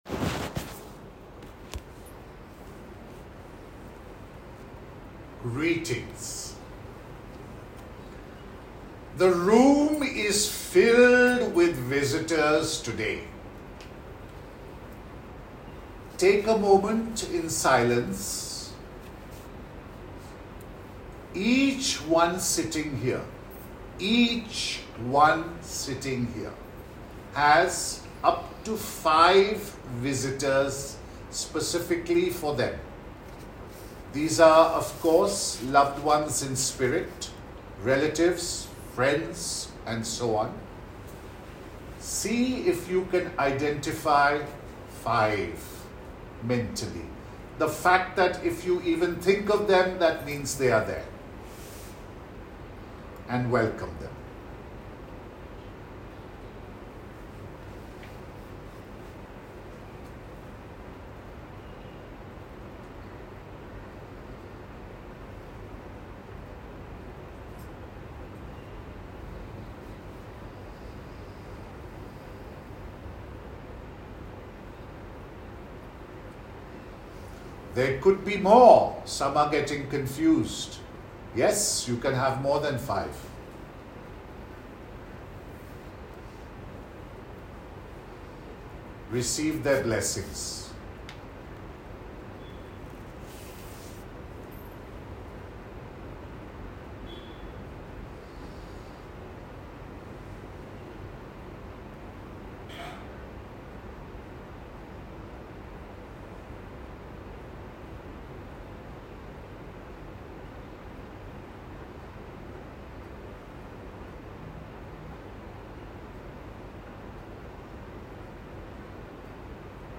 Channeling_28_Nov.m4a